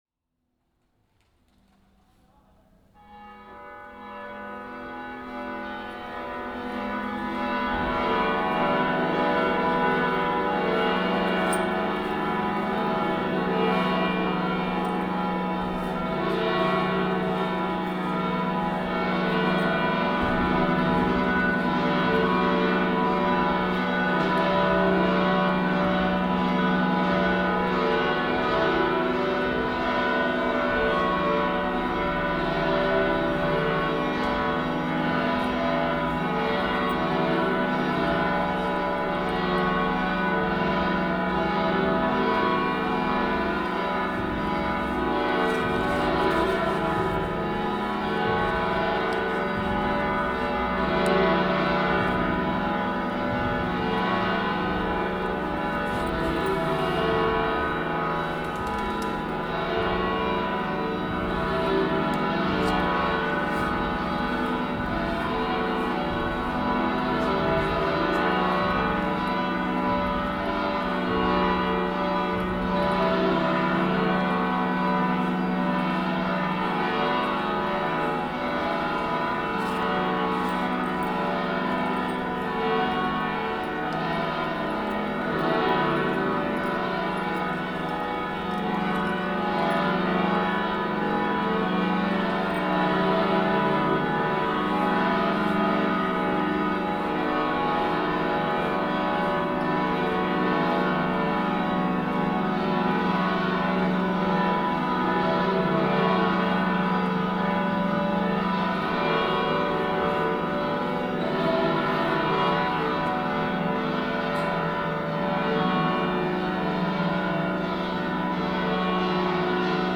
31.4 CAMPANES PREGÓ Grup de Campaners de la Catedral